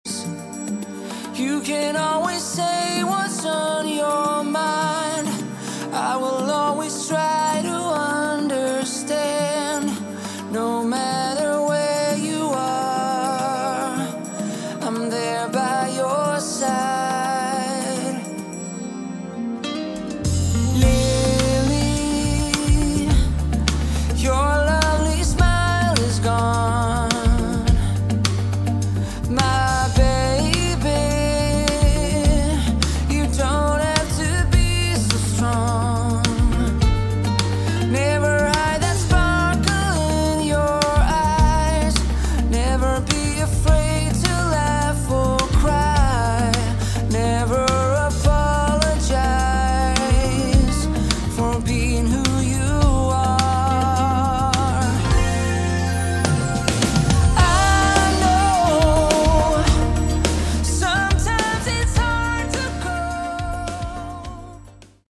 Category: AOR / Melodic Rock
vocals
guitar, bass, keyboards
drums, bass